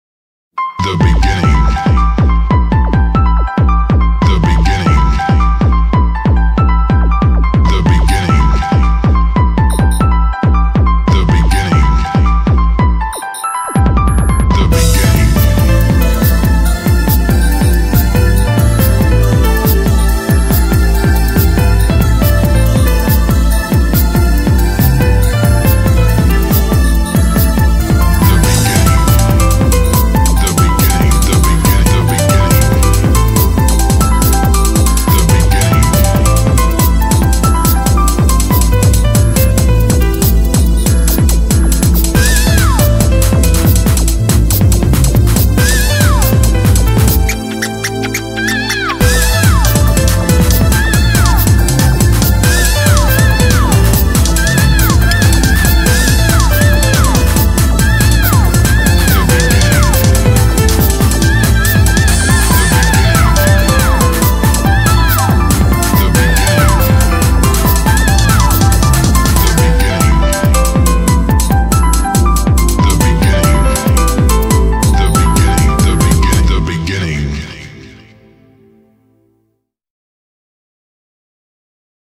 BPM140
[TECHNO]
Really short, but still fun I think.